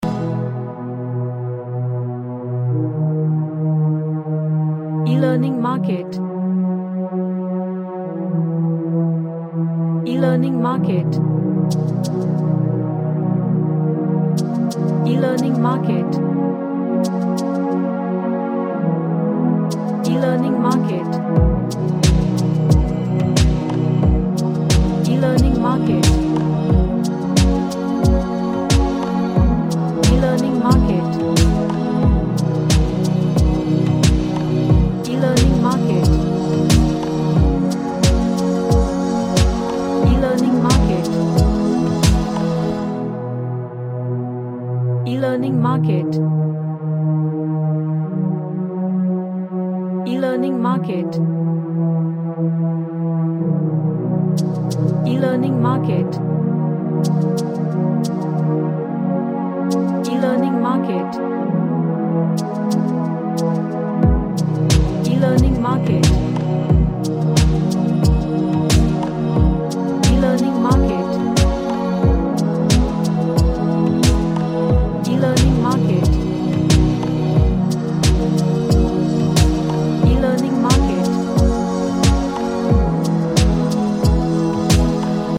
An Ambient track with nebula melody.
Relaxation / Meditation